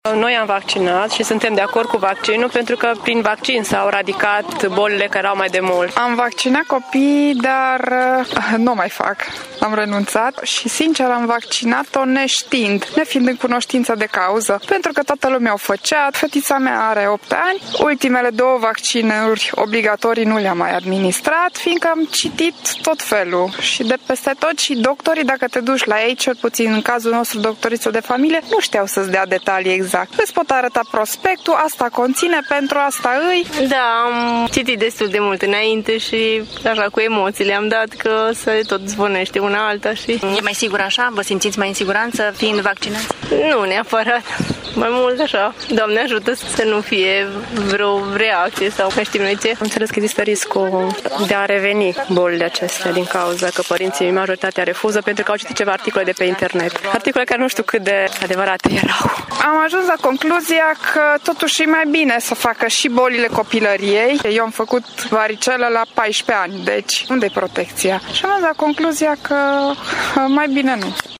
Mamele din Tîrgu-Mureș au păreri împărțite cu privire la vaccinarea copiilor: unele cred că este soluția cea mai bună pentru a-și feri copiii de boli grave, în timp ce altele au renunțat să mai creadă în eficiența vaccinurilor: